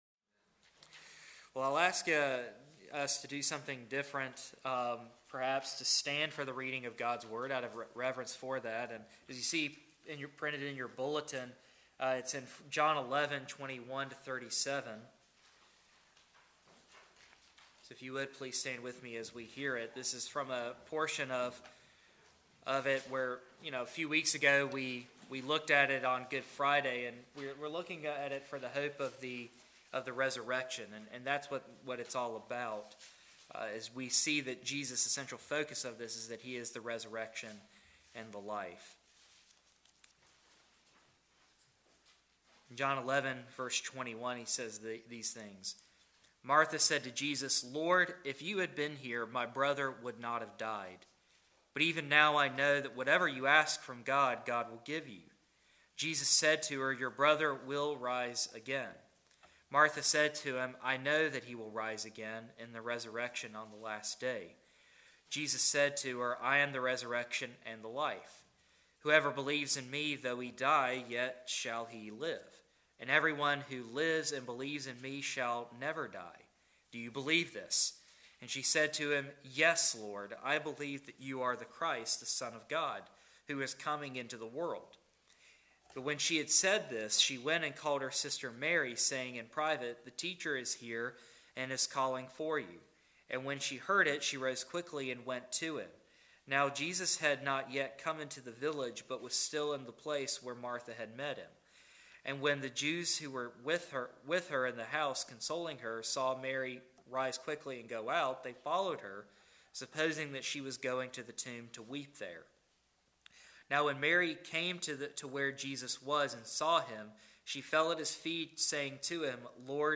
John 11:21-37 Service Type: Sunday Morning John 11:21-37 How do we make sense of the loss of a loved one?